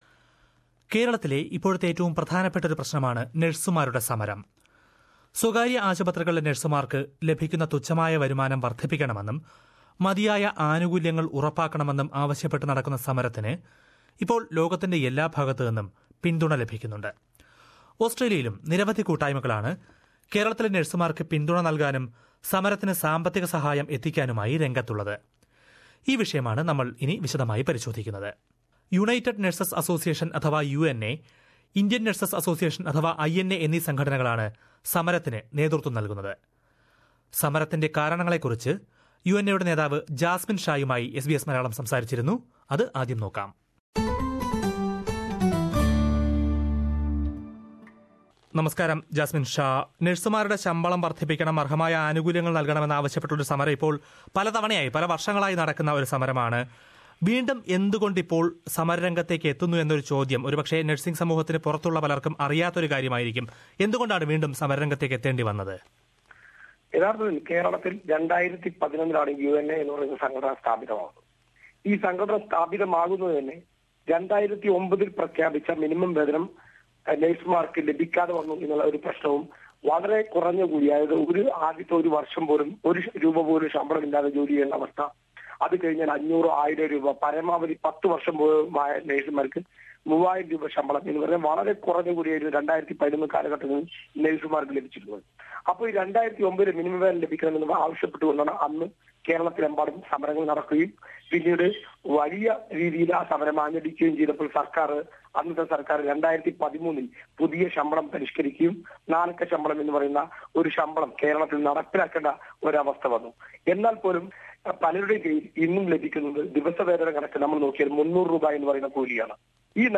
അത്തരത്തിൽ ഓസ്ട്രേലിയയുടെ വിവിധ ഭാഗങ്ങളിലുള്ള മലയാളി നഴ്സുമാർ ഇവർക്ക് പിന്തുണ നൽകിക്കൊണ്ട് പല പ്രതിഷേധ കൂട്ടായ്മകളും സംഘടിപ്പിക്കുന്നുണ്ട്. ഇതേക്കുറിച്ച് ഒരു റിപ്പോർട്ട് കേൾക്കാം മുകളിലെ പ്ലേയറിൽ നിന്ന്...